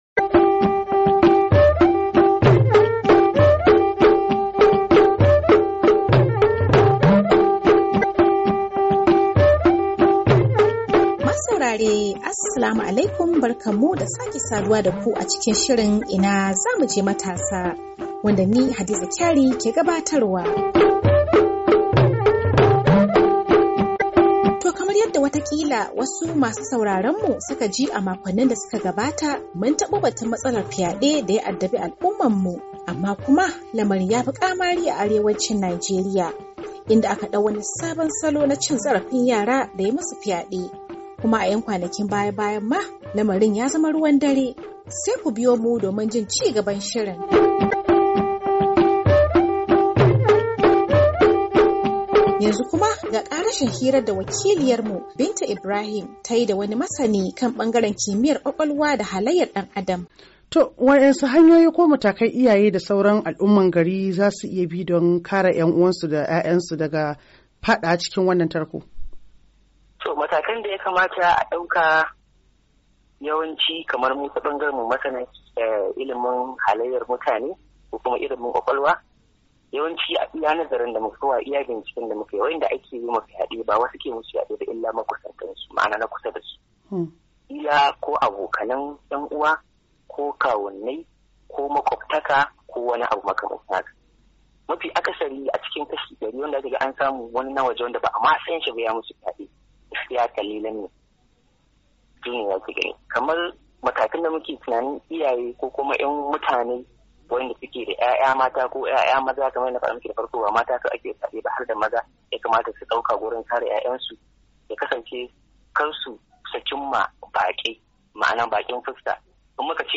INA ZA MU JE MATASA:Hira kan fyade da masani kan bangaren kimiyar kwakwalwa da halayar dan adam